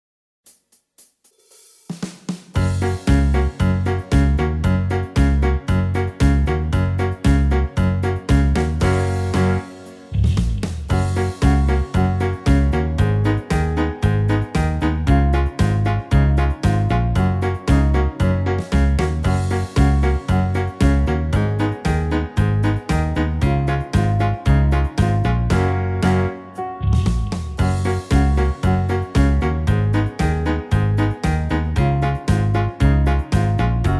utwór w wersji wokalnej oraz instrumentalnej